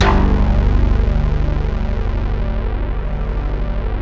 Index of /90_sSampleCDs/Sound & Vision - Gigapack I CD 2 (Roland)/SYN_ANALOG 2/SYN_Analog 8